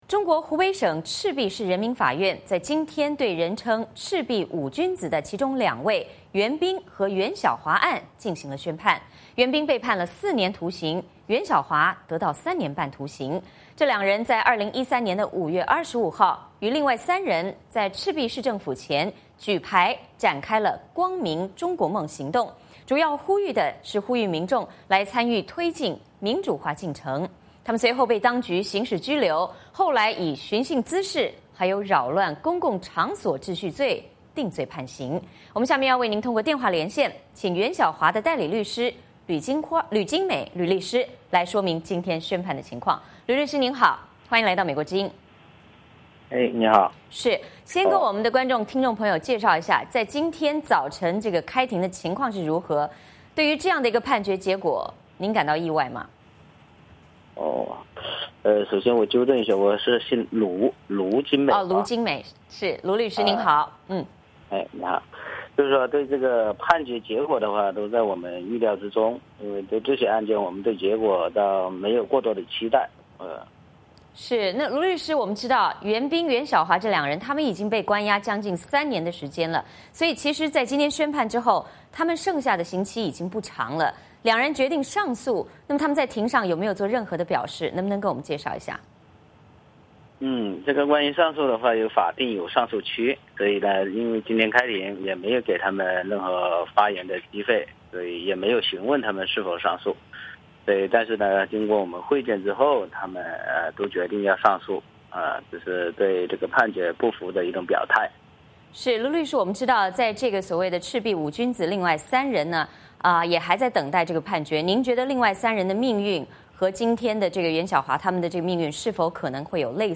我们通过电话连线